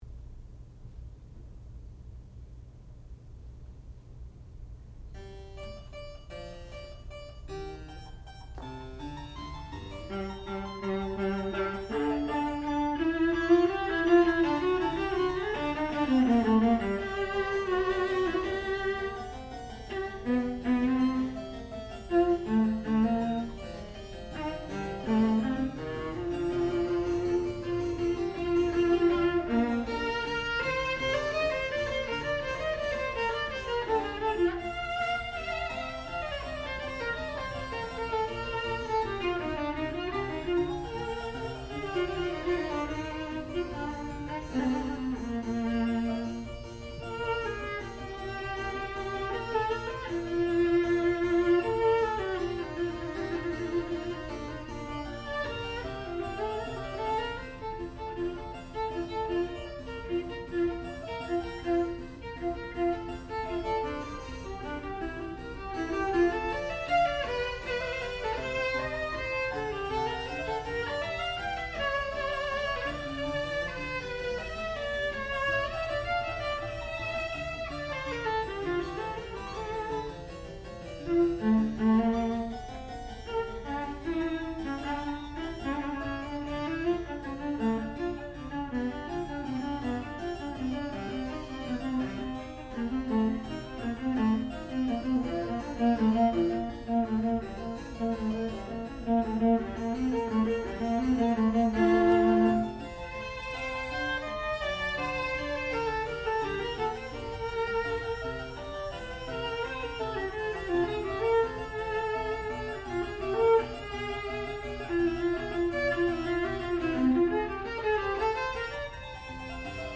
sound recording-musical
classical music
Advanced Recital